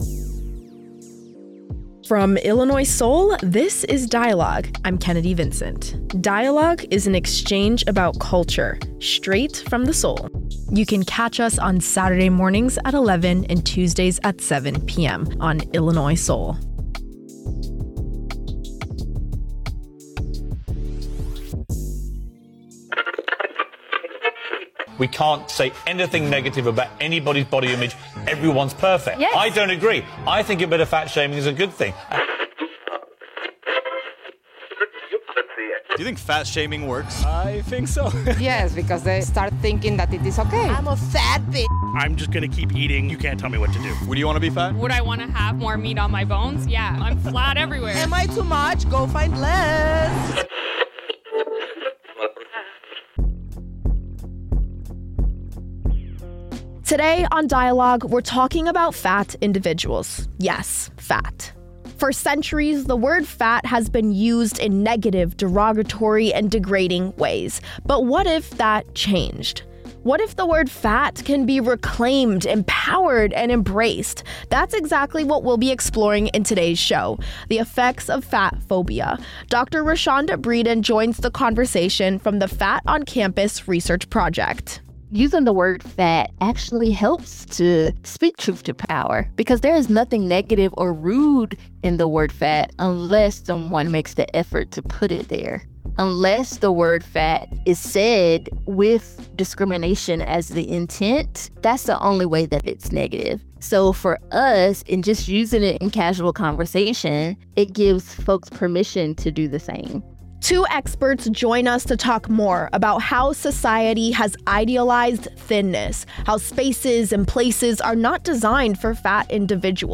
The episode underscores that advocating for a more inclusive society goes beyond language—it's about infrastructure, representation, and recognizing all bodies as worthy of care. Both experts share their personal journeys with body image and how their experiences inform their work.